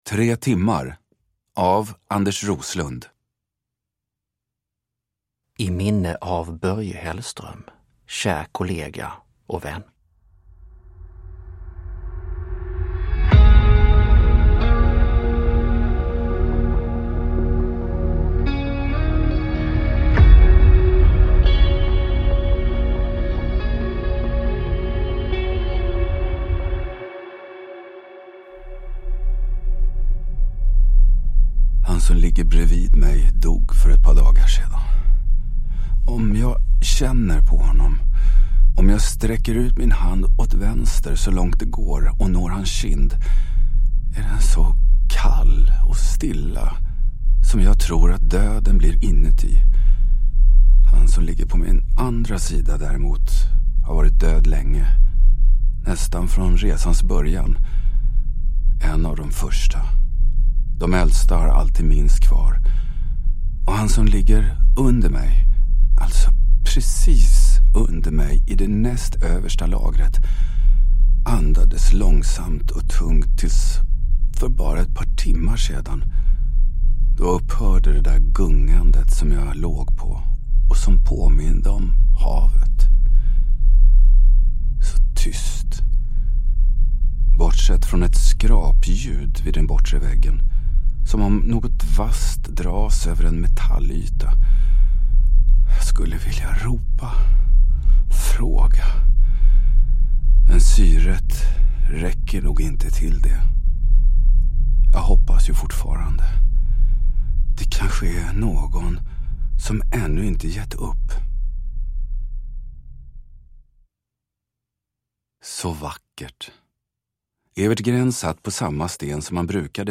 Tre timmar – Ljudbok – Laddas ner
Uppläsare: Thomas Hanzon